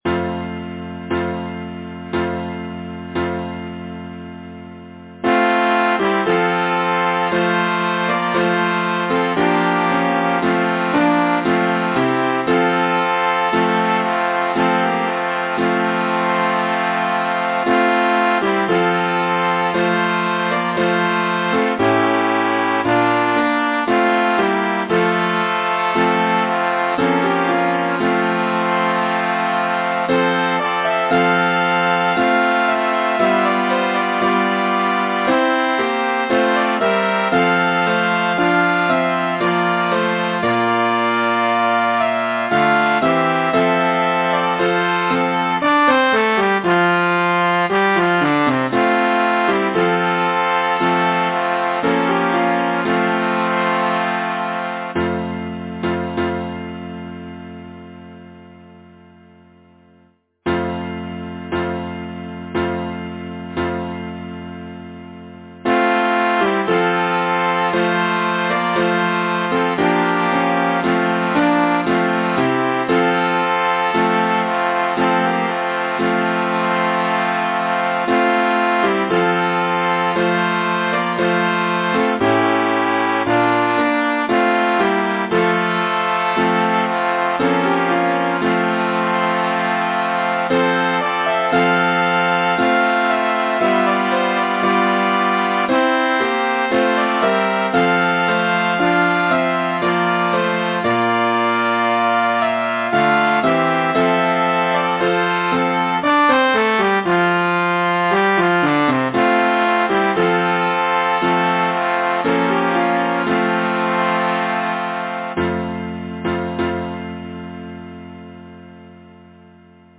Title: As a beam o’er the face of the waters Composer: Anonymous (Traditional) Arranger: Michael William Balfe Lyricist: Thomas Moore Number of voices: 4vv Voicing: SATB Genre: Secular, Partsong, Folksong
Language: English Instruments: A cappella